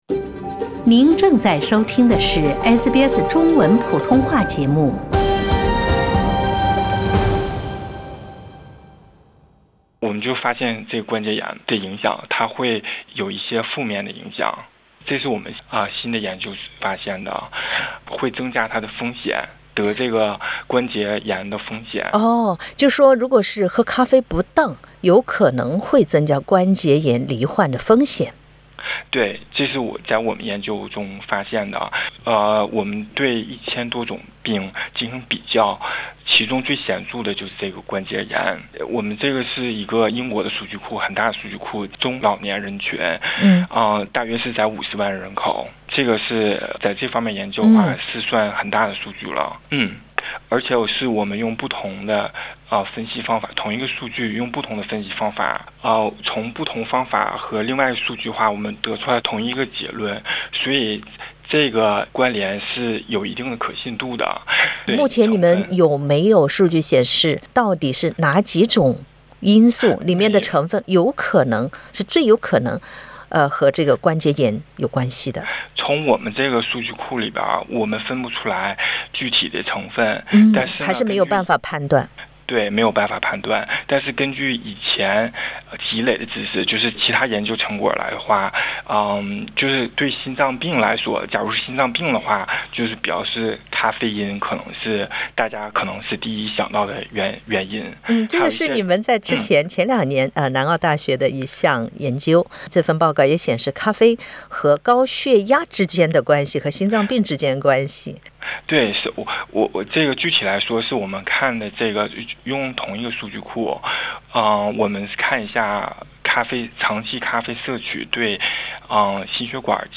一项最新研究显示，摄取过多的咖啡会带来罹患骨关节炎和肥胖症的风险。点击上图收听采访。